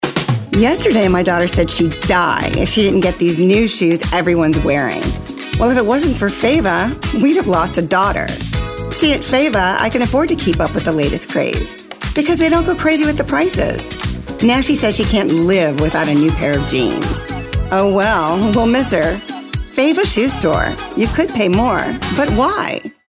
She can deliver a friendly, conversational mom character with ease, or pivot to an energetic and upbeat read for a high-impact ad.
announcer, character, compelling, confident, friendly, humorous, retail, upbeat